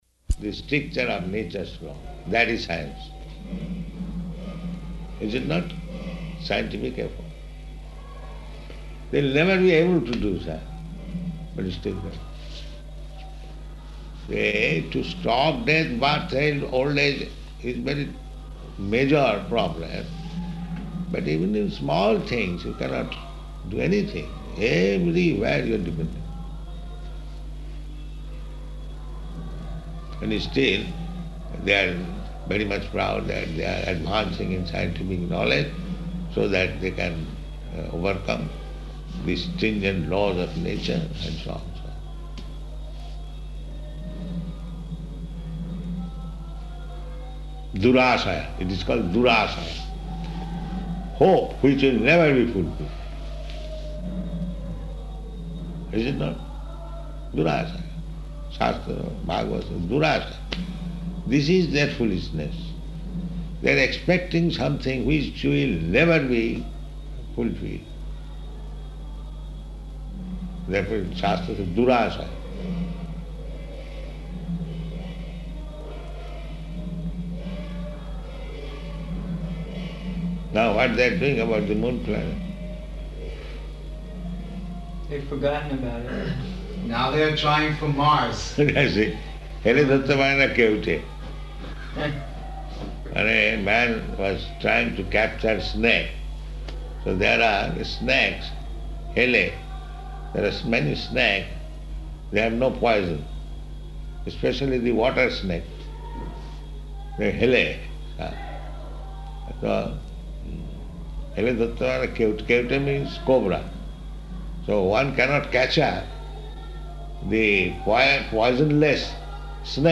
Room Conversation
Room Conversation --:-- --:-- Type: Conversation Dated: January 19th 1976 Location: Māyāpur Audio file: 760119R1.MAY.mp3 Prabhupāda: The stricture of nature's law, that is science.